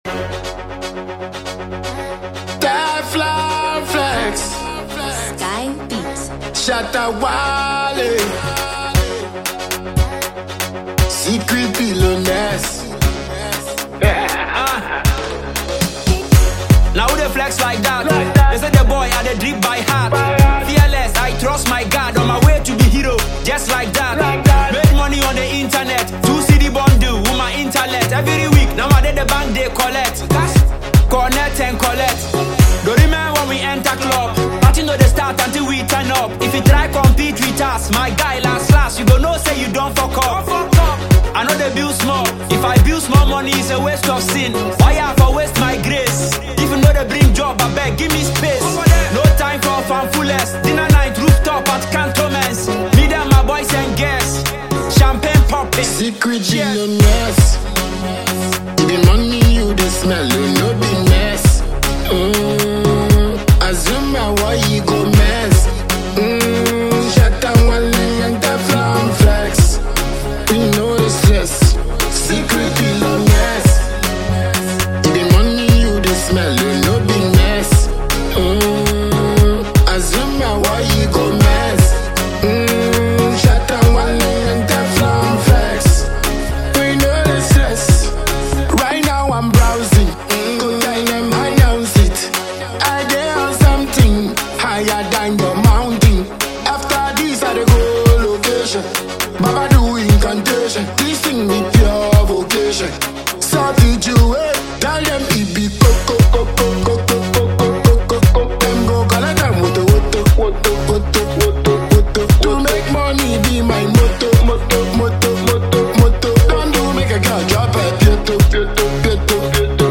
Multiple award-winning dancehall act